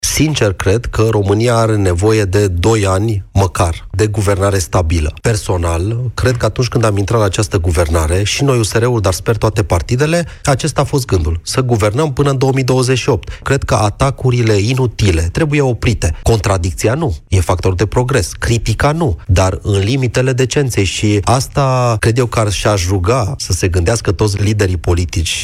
România are nevoie de cel puțin doi ani de guvernare stabilă, pentru a asigura predictibilitatea fiscală și atragerea investițiilor, a subliniat Ministrul Economiei, Irineu Darău la emisiunea Piața Victoriei.
Ministrul Economiei, Irineu Darău: „Cred că România are nevoie de cel puțin doi ani de guvernare stabilă”